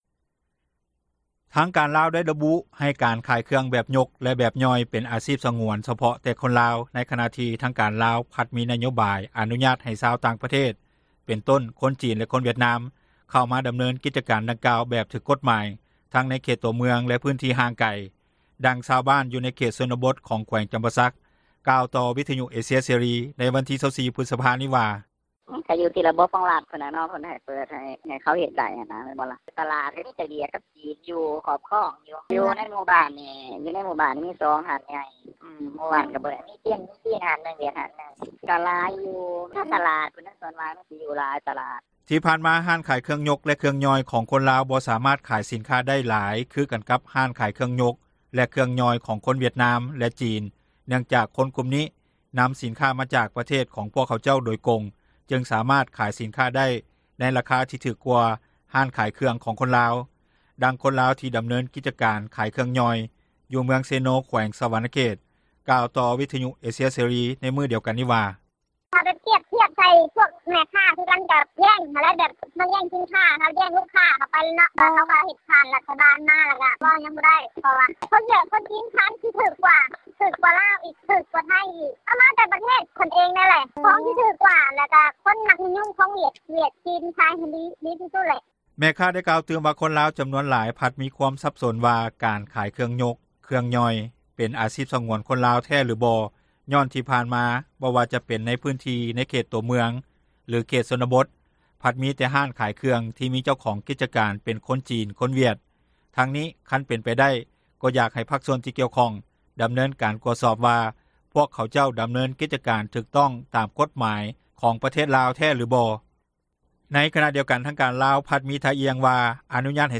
ດັ່ງຊາວບ້ານຢູ່ເຂດຊົນນະບົດ ຂອງ ແຂວງຈຳປາສັກ ກ່າວຕໍ່ວິທຍຸເອເຊັຽເສຣີ ໃນວັນທີ 24 ພຶສພາ ນີ້ວ່າ:
ດັ່ງຄົນລາວທີ່ດຳເນີນກິຈການ ຂາຍເຄື່ອງຫຍ່ອຍ ຢູ່ເມືອງເຊໂນ ແຂວງສວັນນະເຂດ ກ່າວຕໍ່ວິທຍຸເອເຊັຽເສຣີໃນມື້ດຽວກັນວ່າ:
ດັ່ງຊາວບ້ານ ຢູ່ເມືອງທ່າແຂກ ແຂວງຄຳມ່ວນ ທ່ານນຶ່ງກ່າວວ່າ: